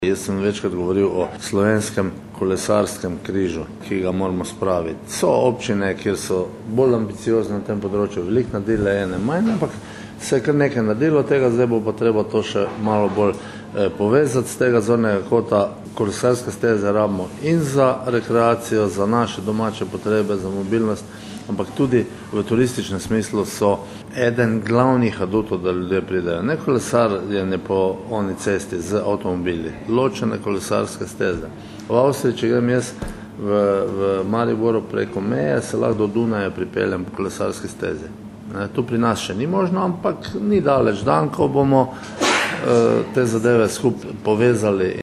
izjava_ministerzdravkopocivalsek.mp3 (1,1MB)